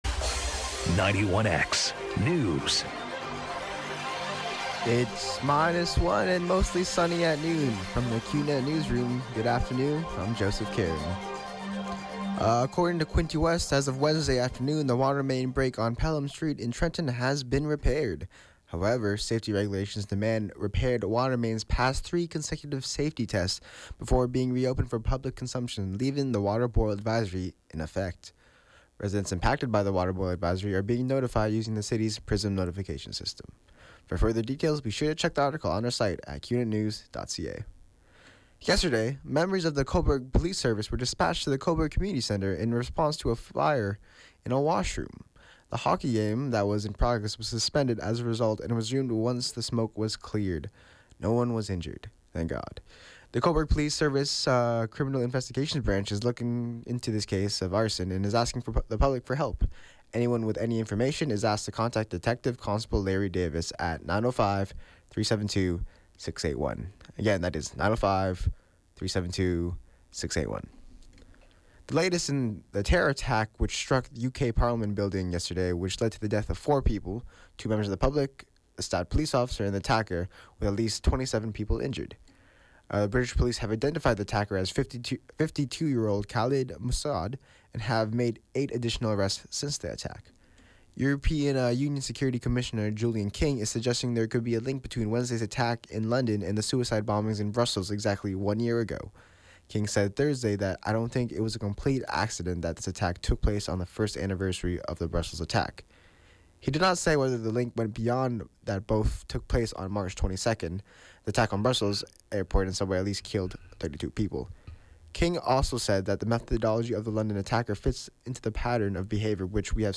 91X FM Newscast – Thursday, March 23, 2017, noon